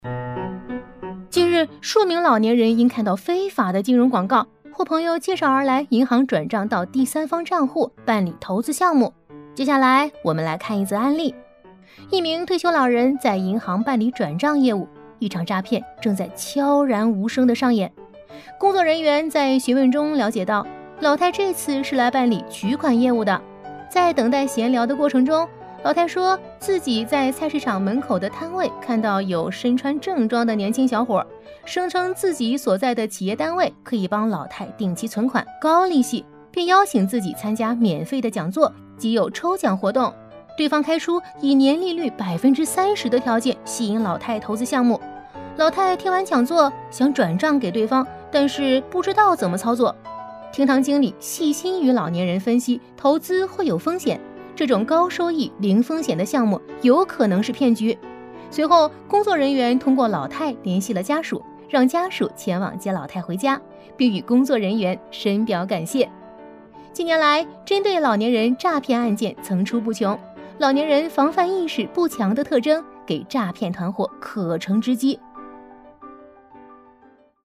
飞碟说-女22-防诈骗.mp3